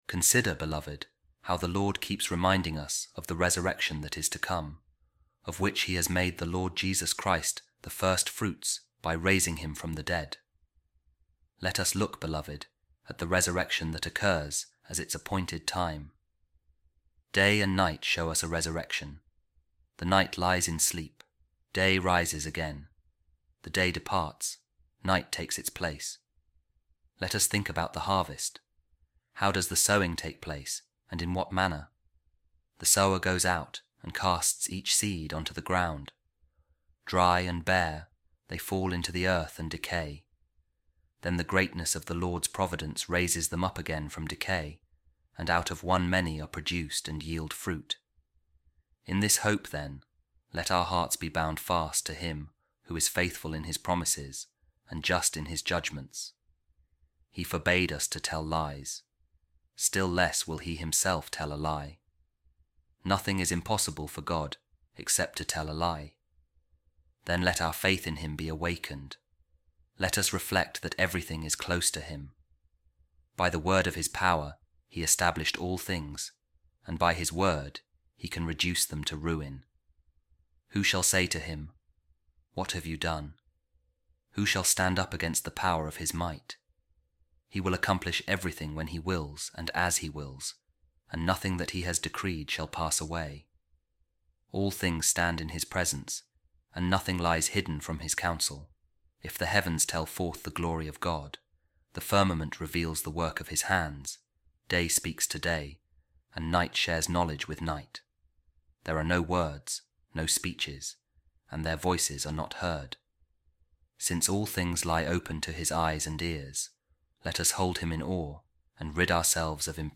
A Reading From The Letter Of Pope Saint Clement I To The Corinthians | God Is Ever True To His Word